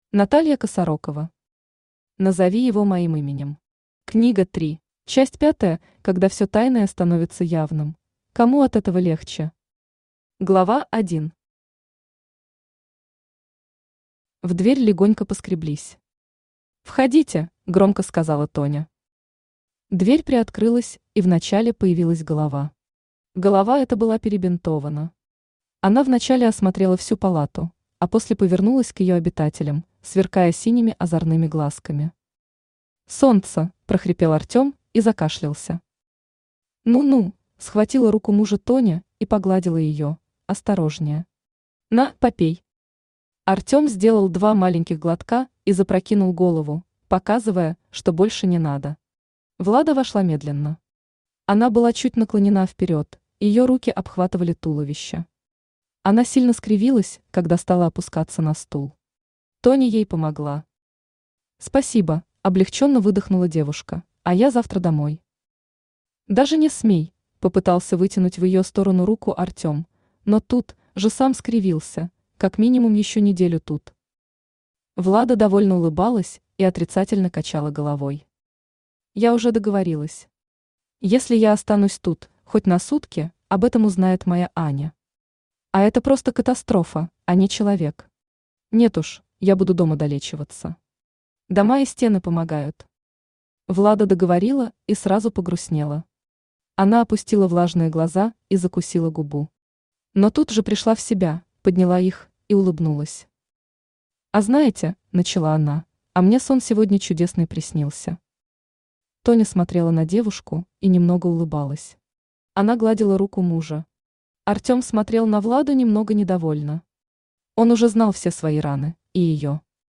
Книга 3 Автор Наталья Владимировна Косарокова Читает аудиокнигу Авточтец ЛитРес.